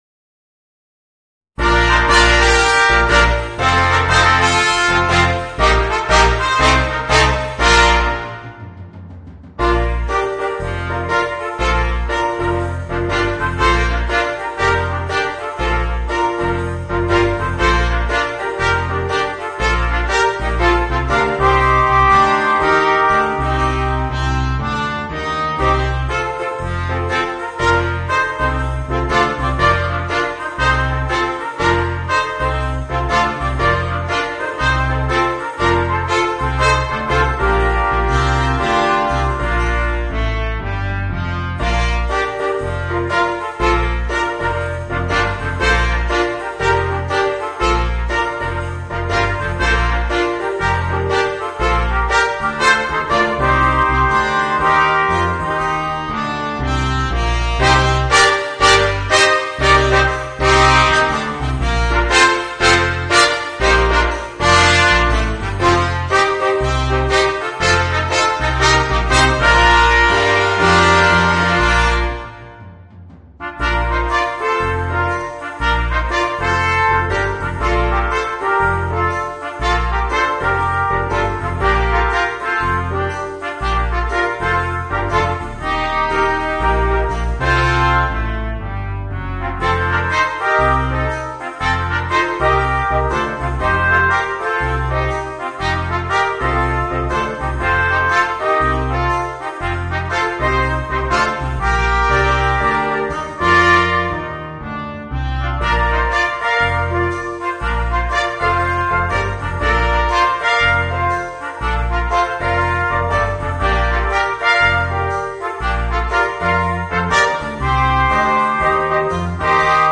Voicing: 5 Trumpets